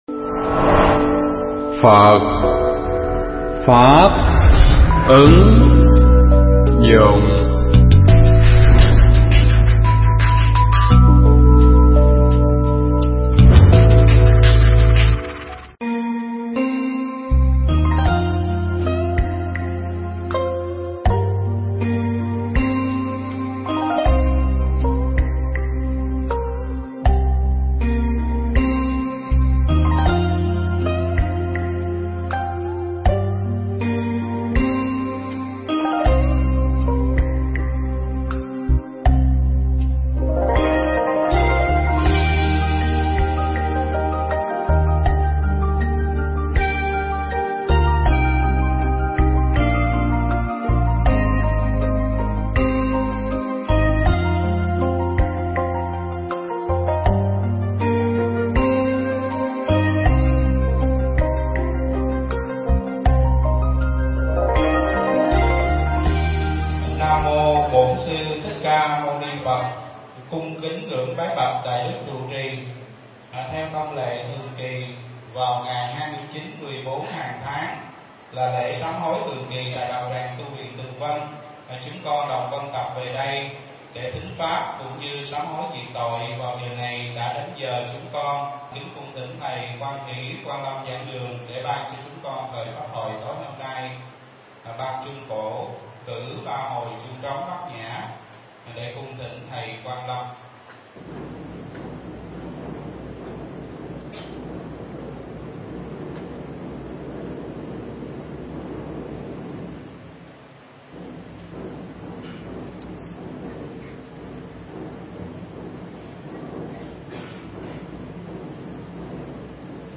Mp3 pháp thoại Kinh Pháp Cú Phẩm Tự Ngã (Câu 161-162)